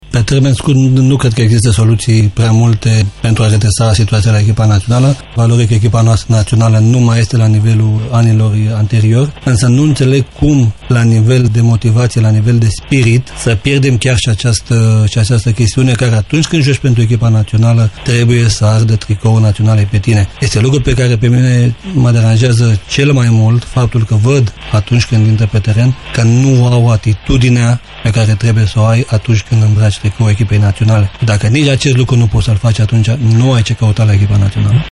Invitat de Ovidiu Ioanițoaia la Europa FM în emisiunea “Tribuna Zero”, fostul căpitan al echipei naționale a mărturisit că nu mai are motivația necesară pentru a ocupa o funcție de conducere: